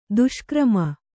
♪ duṣkrama